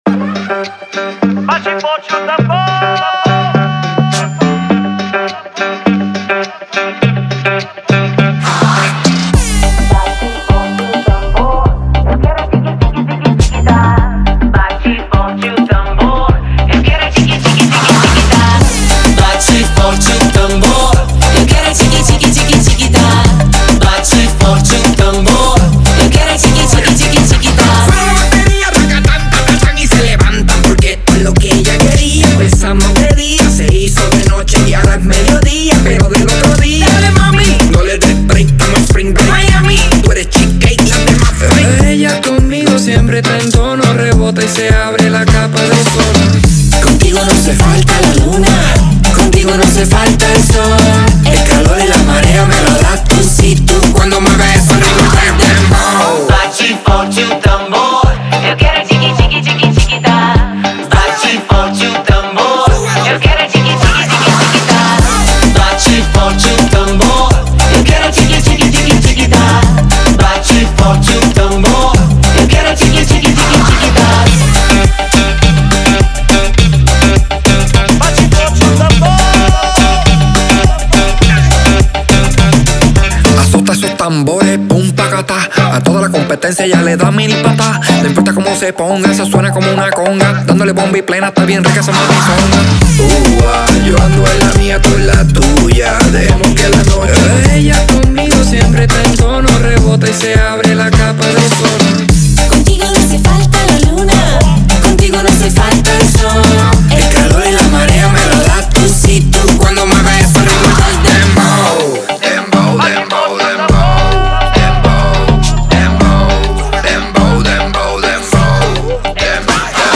это зажигательный трек в жанре eurodance